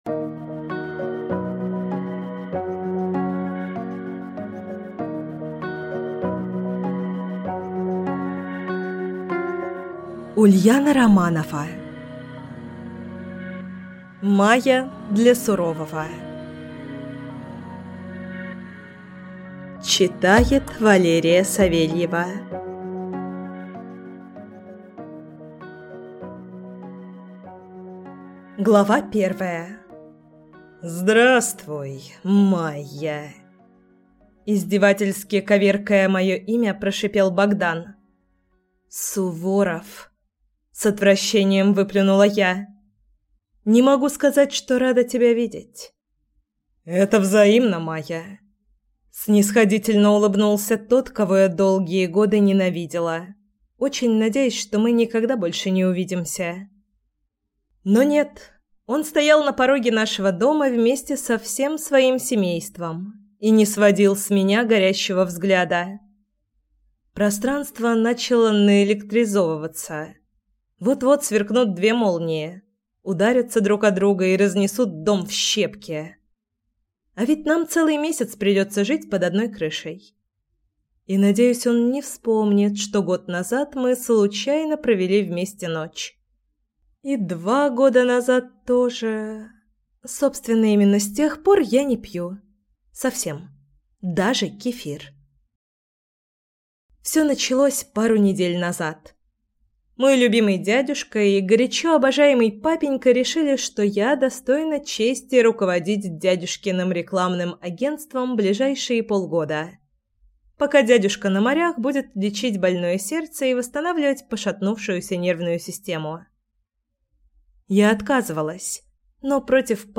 Аудиокнига Майя для сурового | Библиотека аудиокниг
Прослушать и бесплатно скачать фрагмент аудиокниги